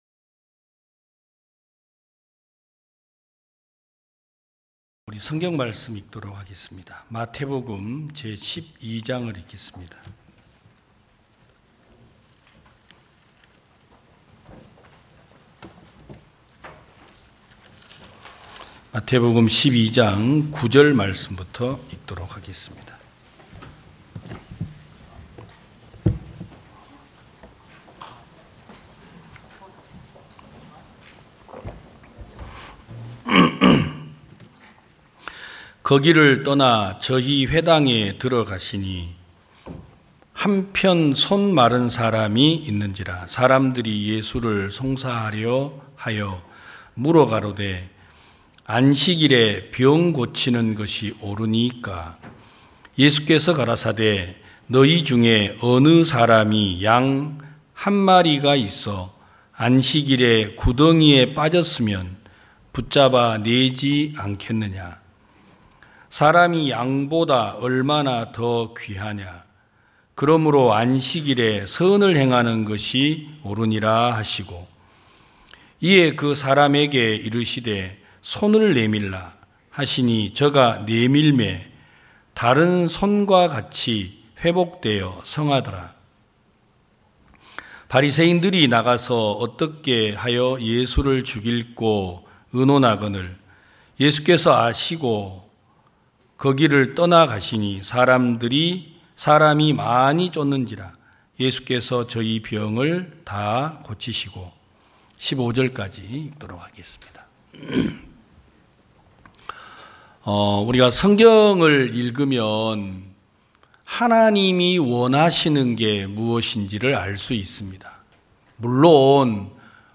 2022년 08월 21일 기쁜소식부산대연교회 주일오전예배
성도들이 모두 교회에 모여 말씀을 듣는 주일 예배의 설교는, 한 주간 우리 마음을 채웠던 생각을 내려두고 하나님의 말씀으로 가득 채우는 시간입니다.